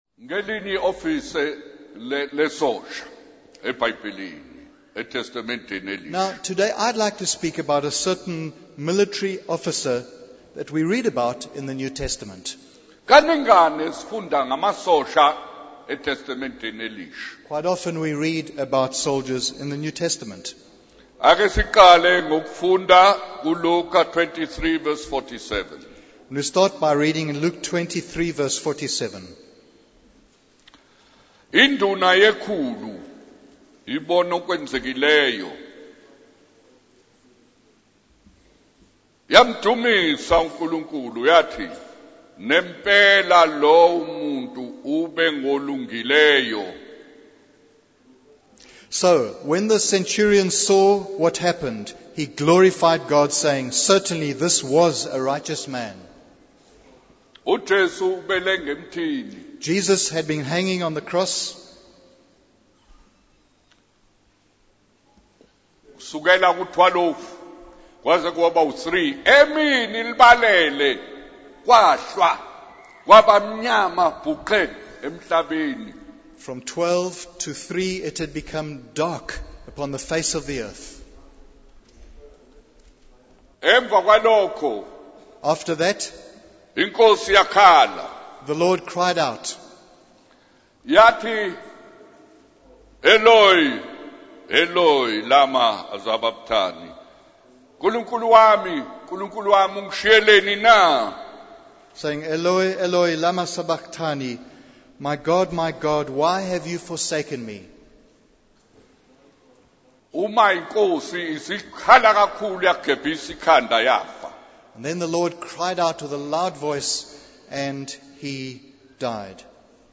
In this sermon, the speaker focuses on the centurion who witnessed Jesus' crucifixion and proclaimed him to be a righteous man.